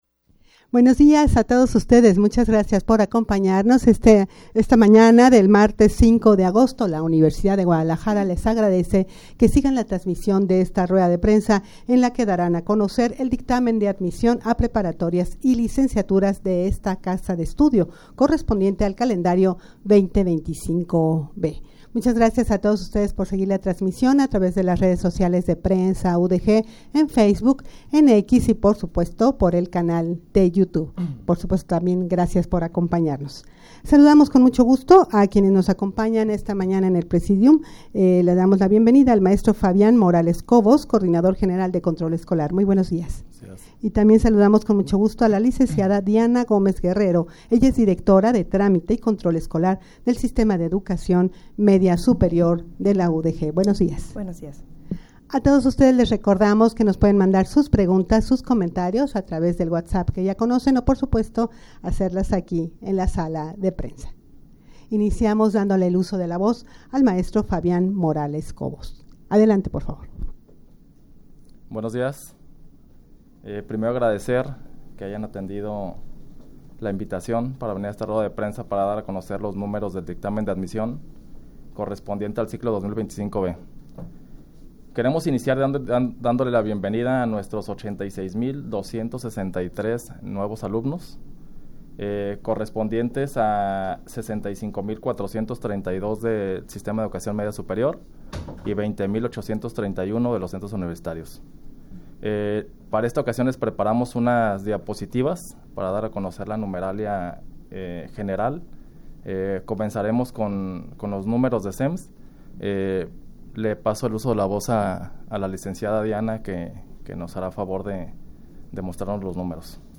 Audio de la Rueda de Prensa
rueda-de-prensa-para-dar-a-conocer-el-dictamen-de-admision-a-preparatorias-y-licenciaturas-de-esta-casa-de-estudio.mp3